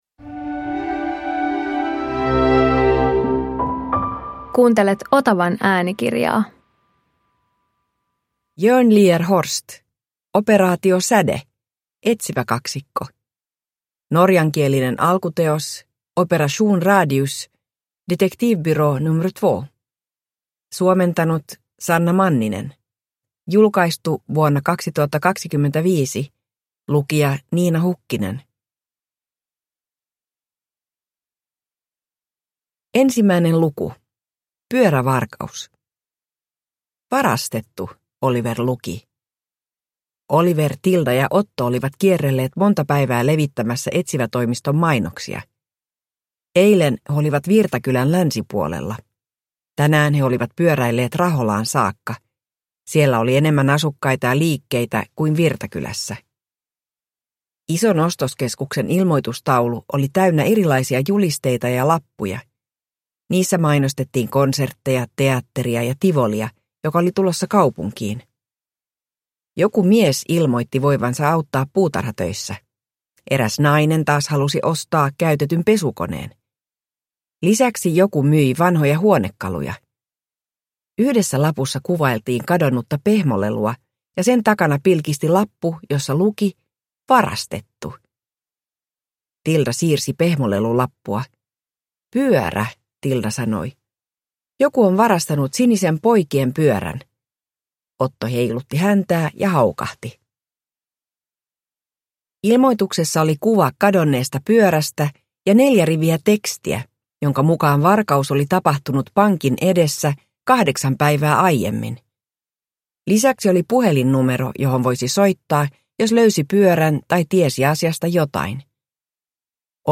Operaatio Säde – Ljudbok